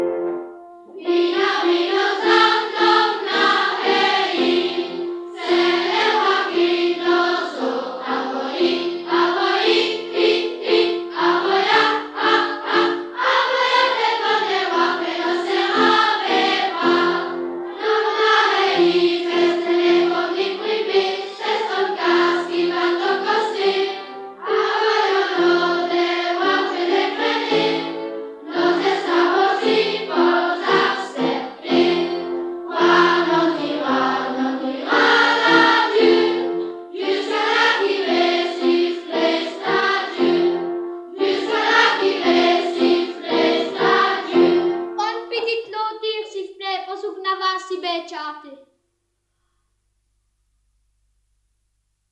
Genre : chant
Type : chanson de quête
Interprète(s) : Anonyme (enfant)
Lieu d'enregistrement : Malmedy
Support : bande magnétique
Chantée par une chorale d'enfants avec accompagnement au piano.